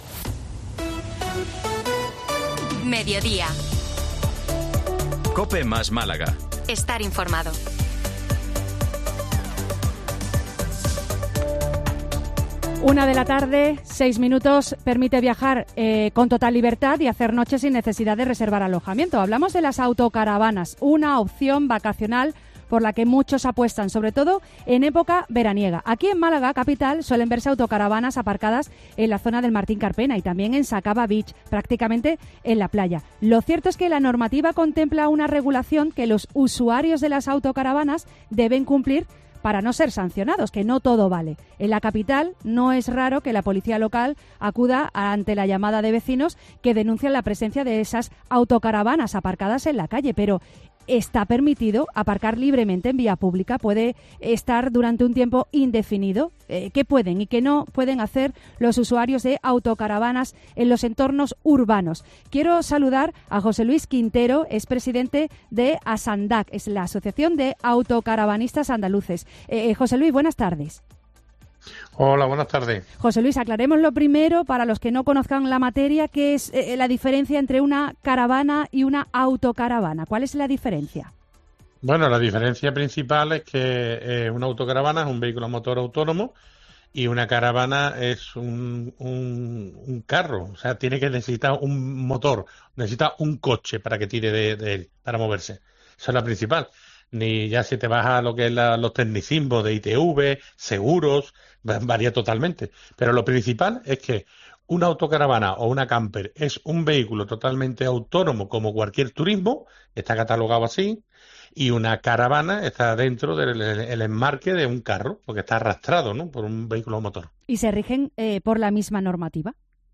Un experto explica en COPE las diferencias entre autocaravana y caravana y advierte que la legislación no es la misma para las dos